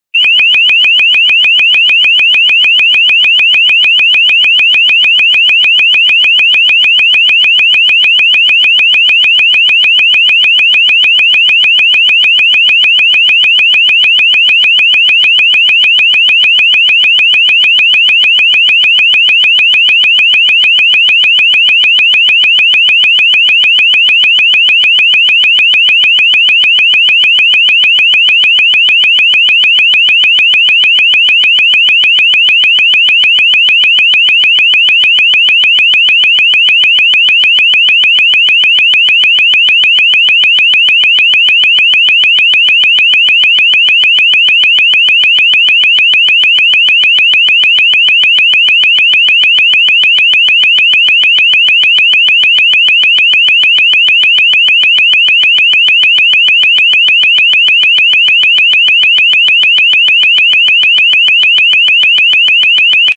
While the system can play any sound, a “chirp” that sweeps every frequency is often used; it has a lot of advantages but I find that it’s incredibly hard to ignore at any volume.
The higher pitches of the chirp are able to cut through the deepest of sleeps without being loud enough to wake my family sleeping in the other rooms.
LRAD Chirp
LRAD-Chirp.mp3